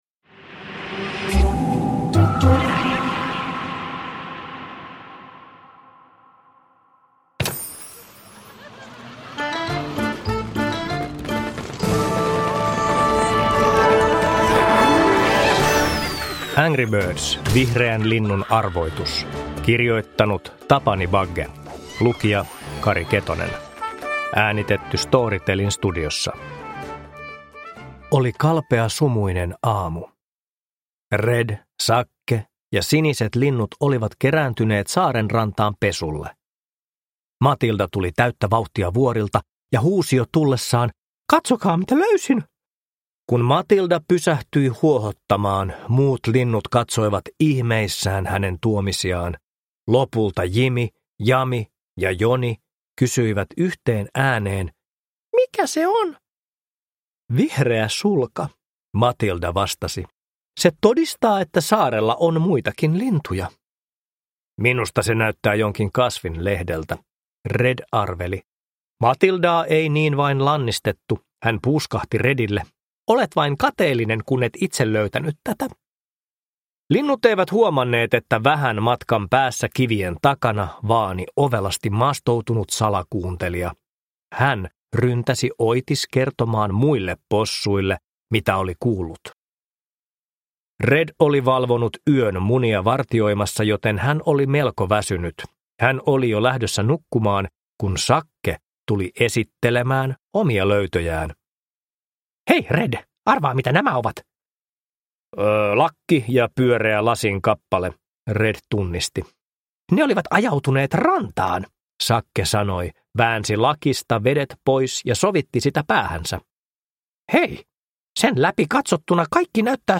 Angry Birds: Vihreän linnun arvoitus – Ljudbok – Laddas ner